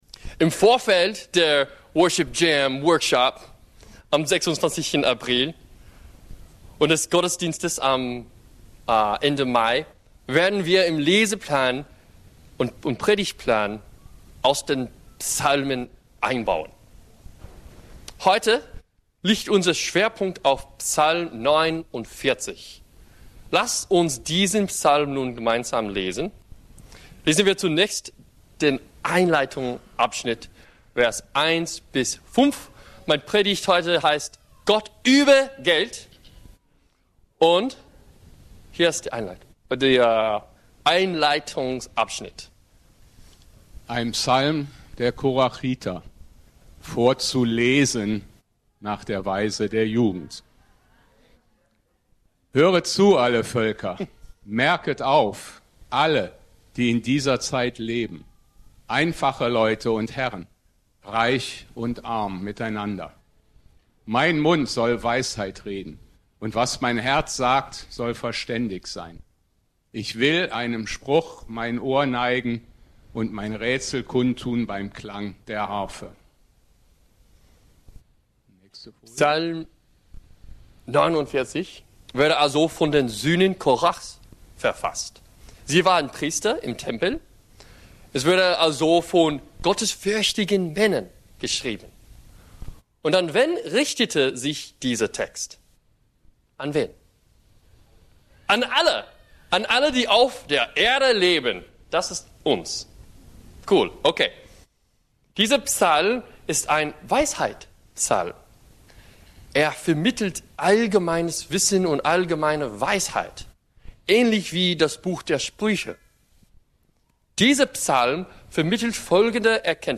Gott über Geld ~ BGC Predigten Gottesdienst Podcast